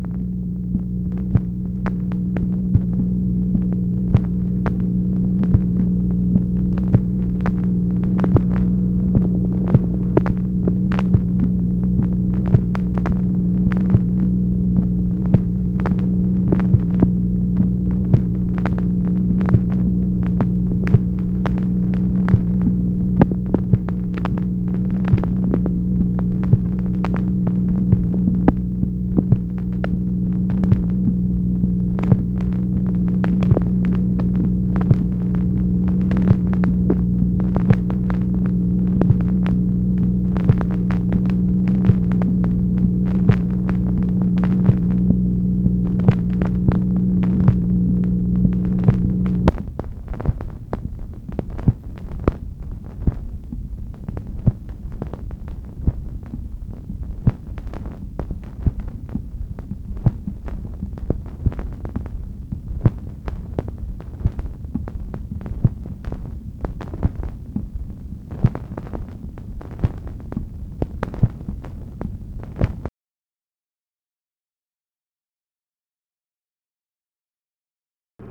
MACHINE NOISE, January 24, 1964 | Miller Center
Secret White House Tapes